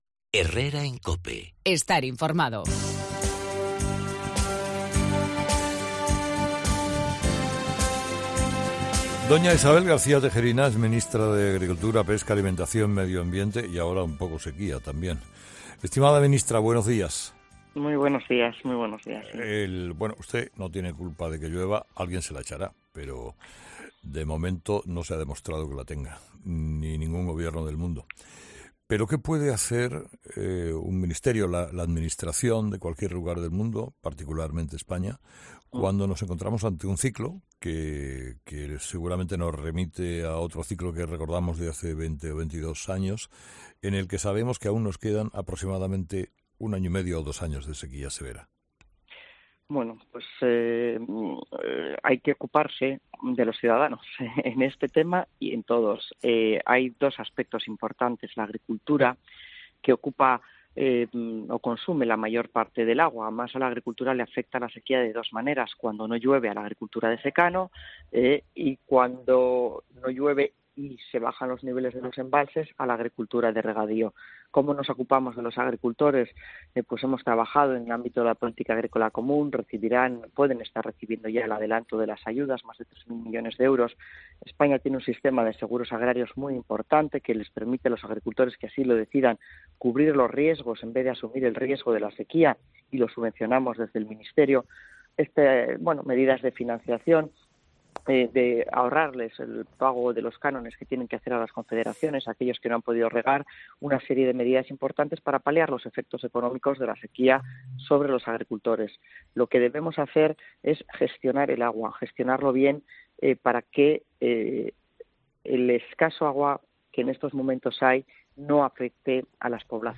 Escucha la entrevista a la ministra de Agricultura, Pesca, Medio Ambiente y Alimentación, Isabel García Tejerina